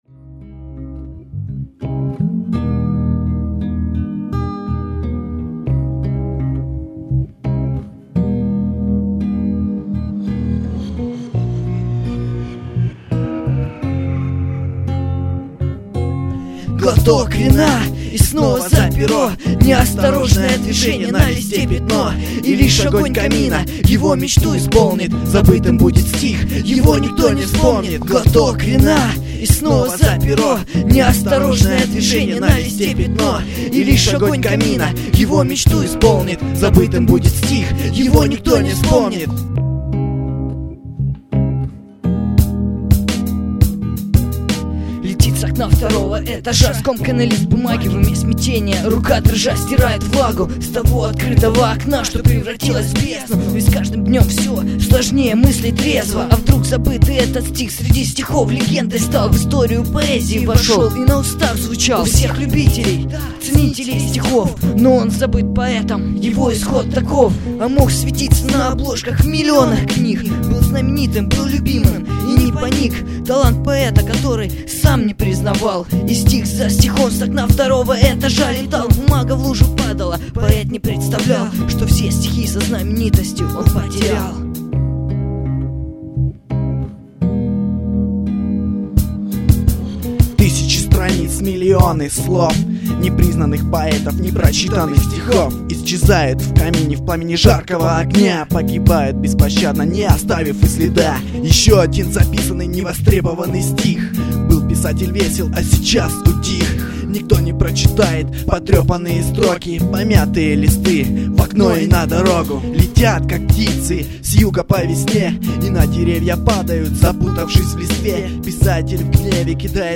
Трек старый, записан в 2005 году, демо версия.